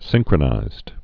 (sĭngkrə-nīzd, sĭn-)